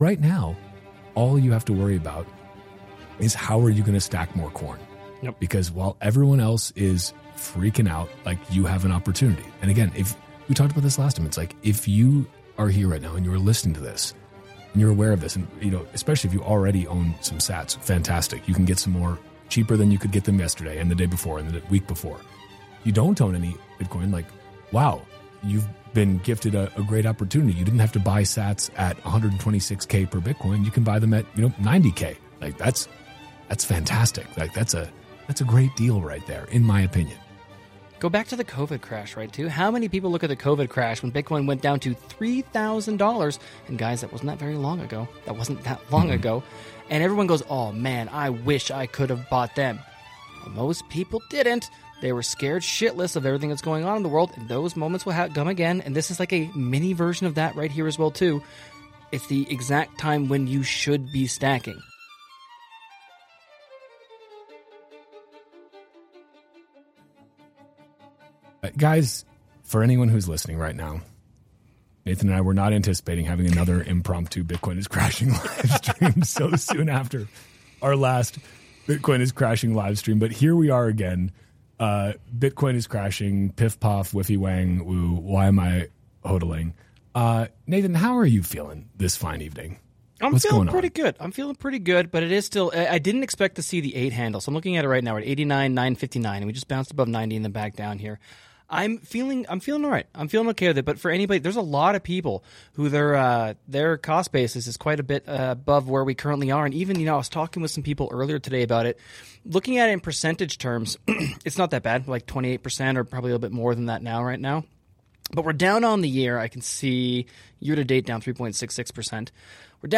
These episodes are originally live streamed as "emergency broadcasts" and I figured I might as well put them out as regular episodes.